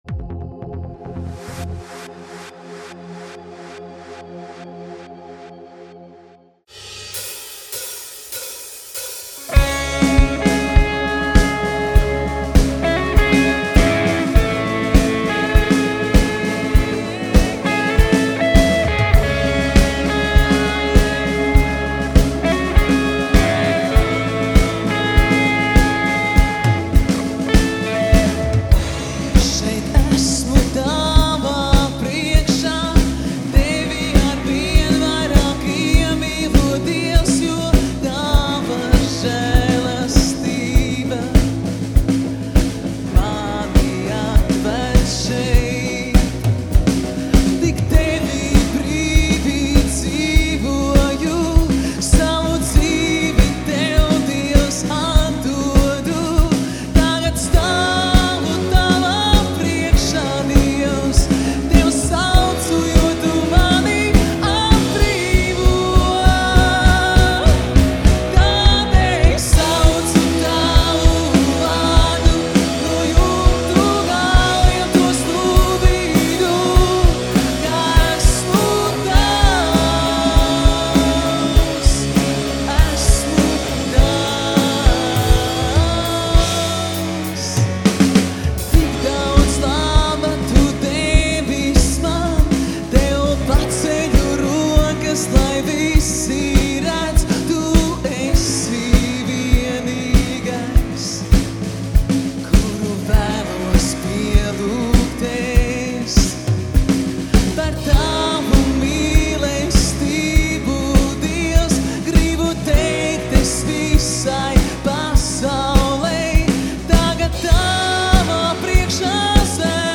Slavēšana 2014 - Kristus Pasaulei